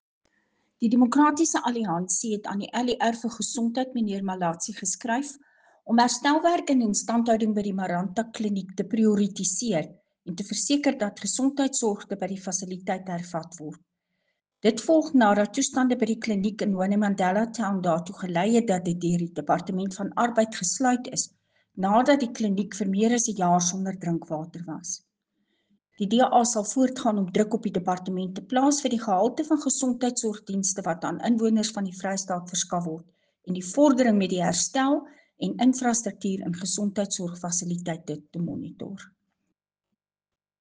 Afrikaans soundbite by Cllr Marieta Visser.